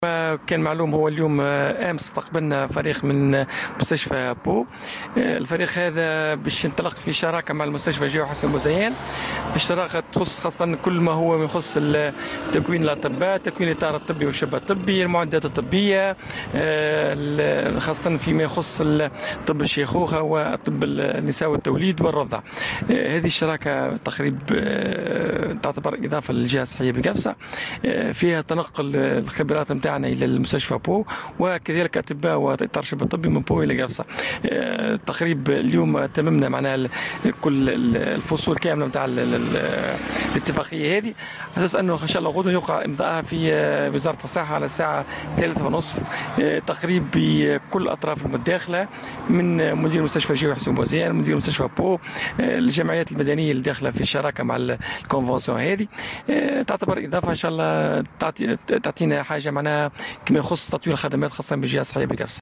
وقال المدير الجهوي للصحة بقفصة، سالم ناصري اليوم في تصريح لمراسل "الجوهرة أف أم" بالجهة إنه من المنتظر أن يتم يوم غد الثلاثاء، إبرام هذه الاتفاقية بمقر وزارة الصحة.